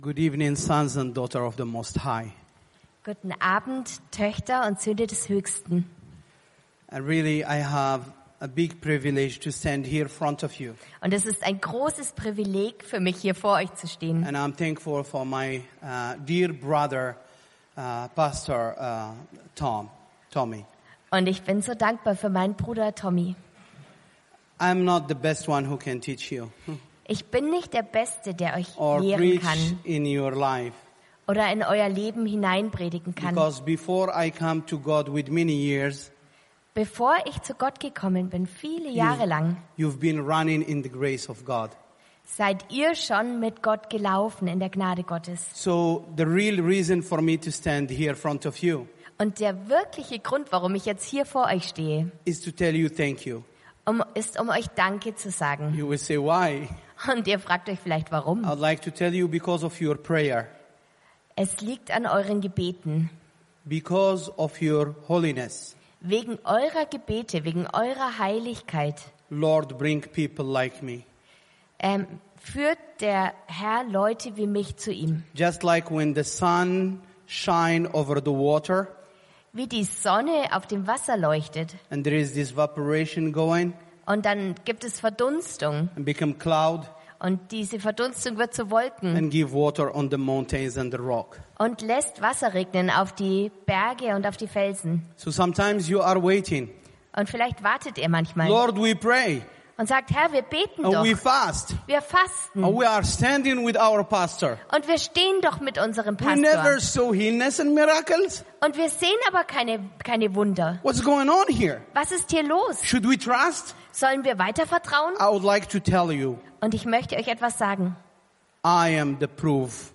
Predigt (Das anschließende Kreuzverhör ist als extra Podcast gespeichert)